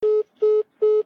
tradeRejected.ogg